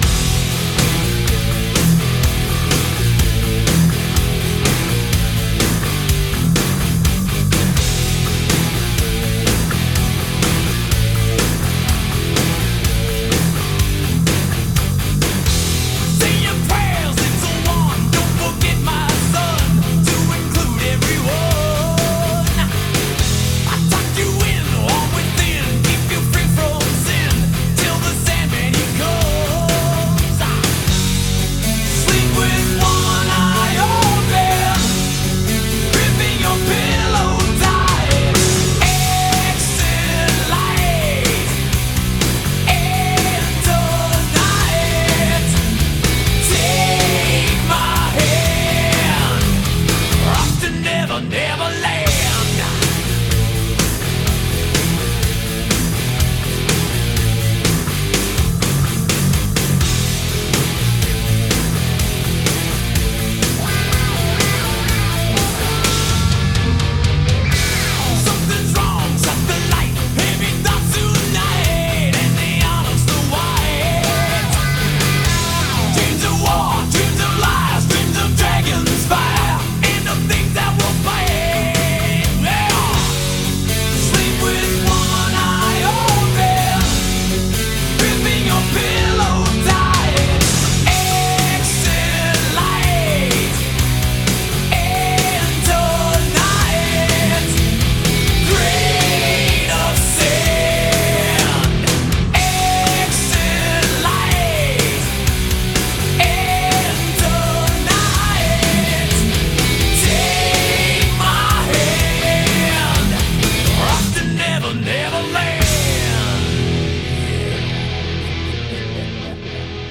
BPM123-126